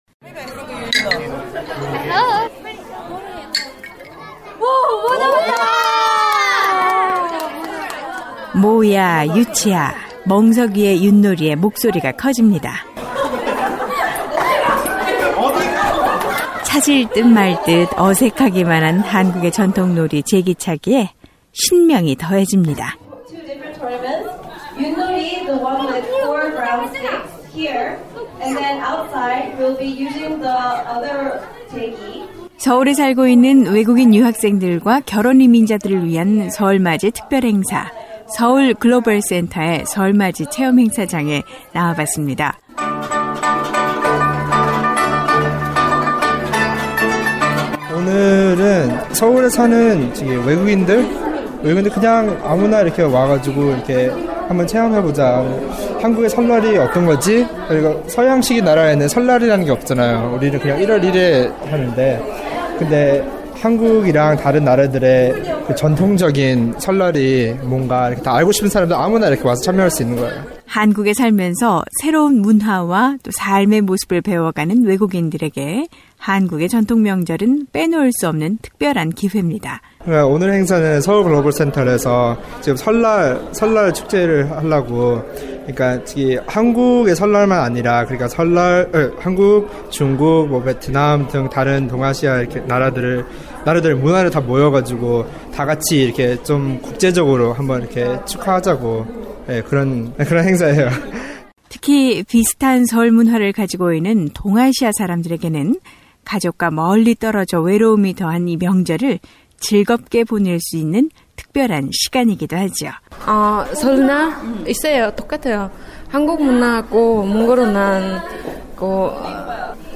오늘은 한민족의 전통 설 명절을 맞은 서울 사는 외국인들의 목소리를 전해드립니다.